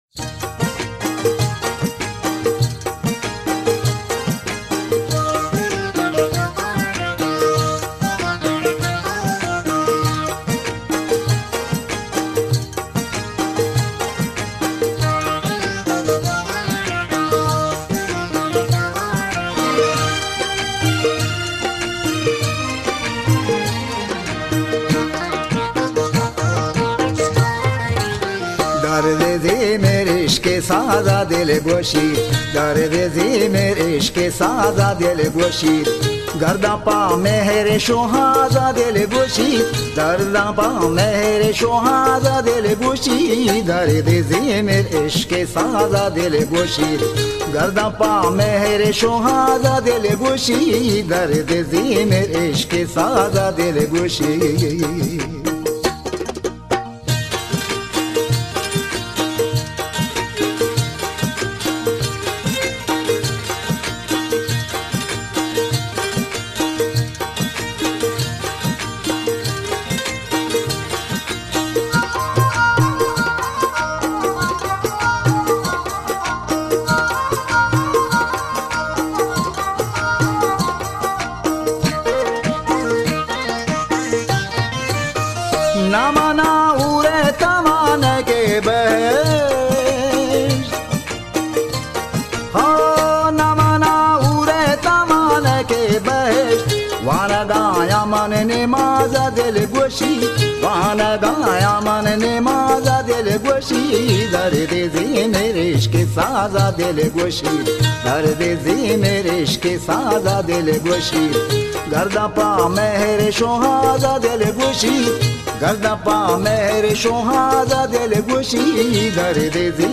آهنگ بلوچی
یکی از خوانندگان بلوچی